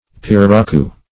Search Result for " pirarucu" : The Collaborative International Dictionary of English v.0.48: Pirarucu \Pi`ra*ru"cu\, n. [From the native South American name.]
pirarucu.mp3